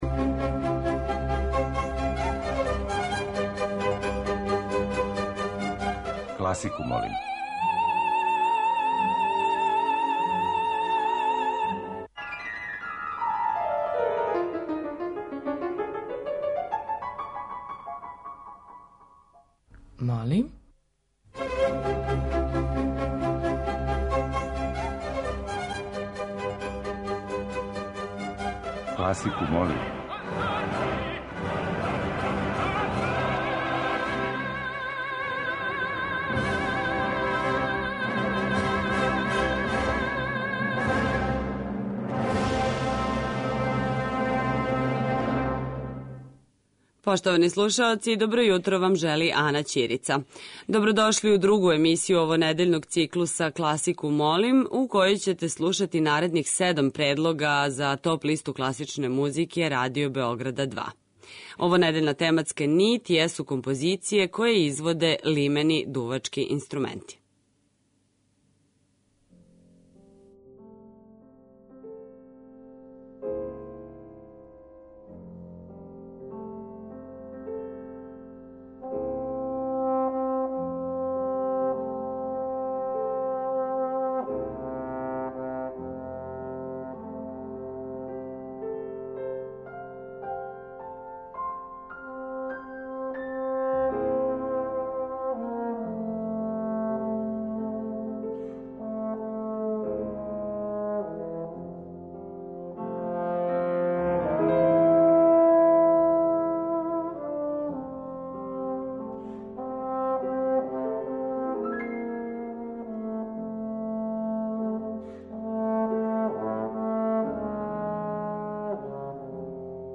Листа предлога за хит класичне музике Радио Београда 2 ове седмице је обједињена композицијама за лимене дувачке инструменте.
Уживо вођена емисија, окренута широком кругу љубитеља музике, разноврсног је садржаја, који се огледа у подједнакој заступљености свих музичких стилова, епоха и жанрова.